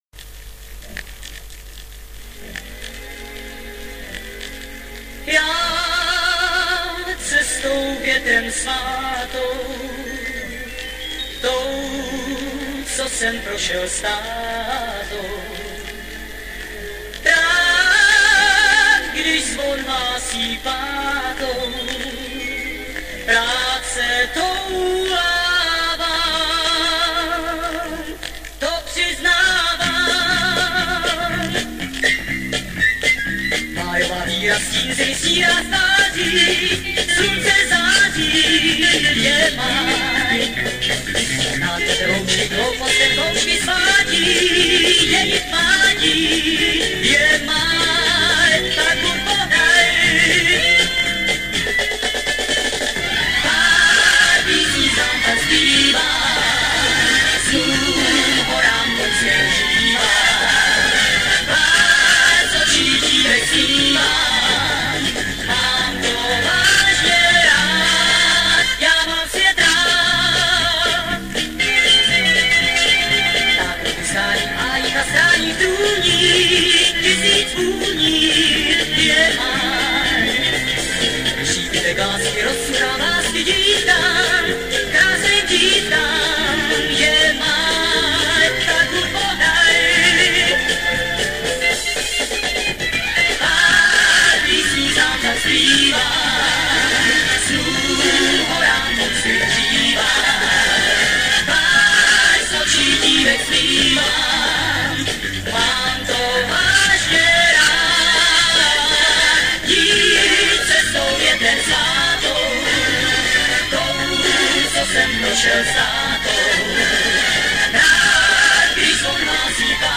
Format: Vinyl, 7", 33 ⅓ RPM, EP, Mono
Genre: Rock, Pop
Style: Pop Rock, Schlager